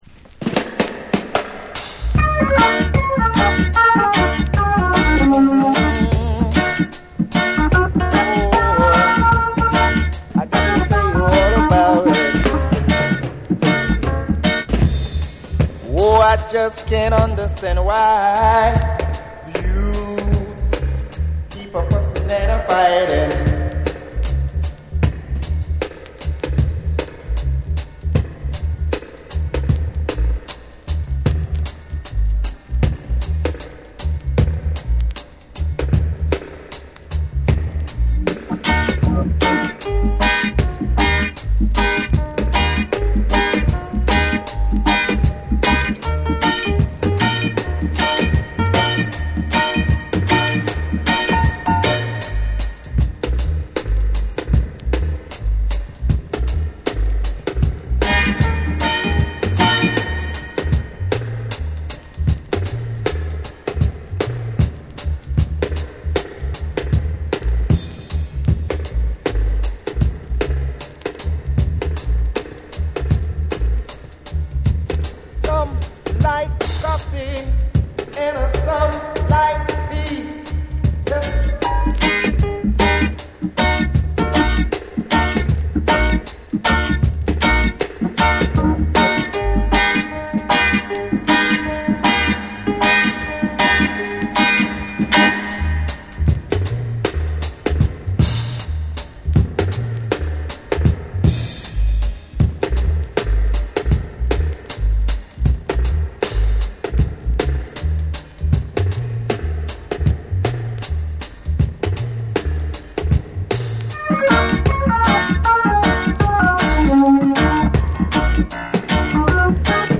鋭いベースと宇宙的エコーが全編を貫き